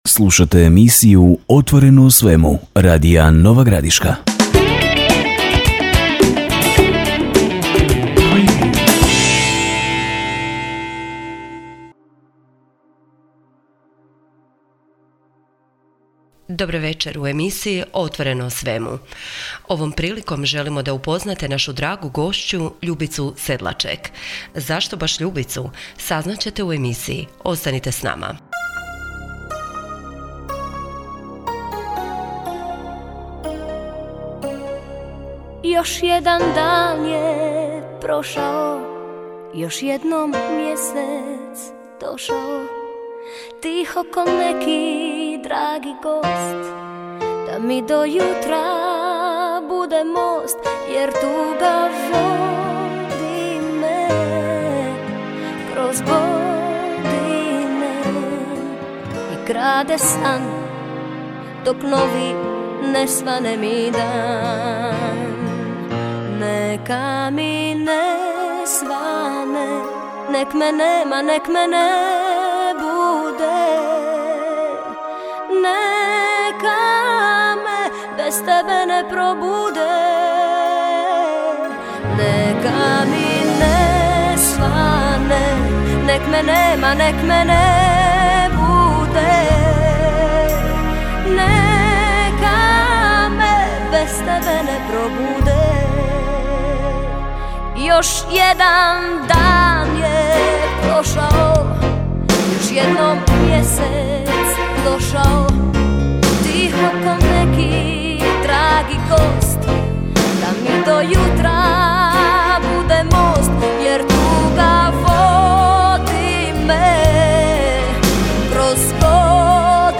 Radijska emisija: “OTVORENO O SVEMU” Gošća emisije